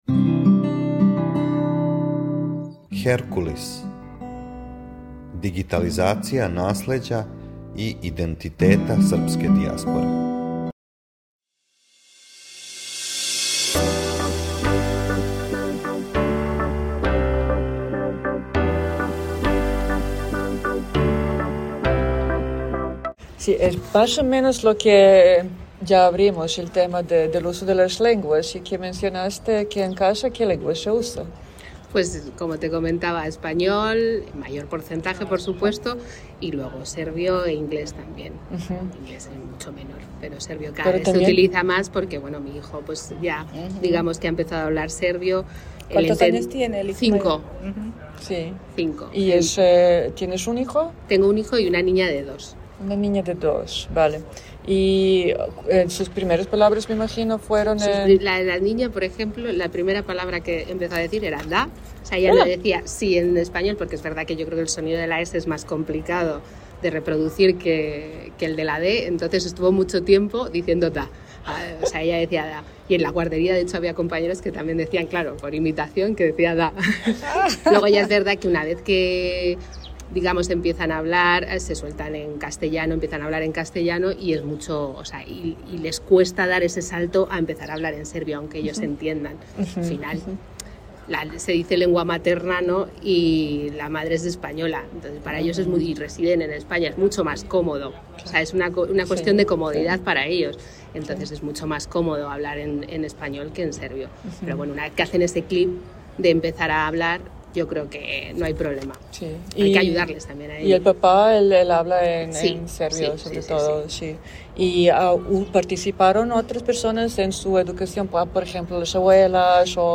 Језик интервјуа:
женски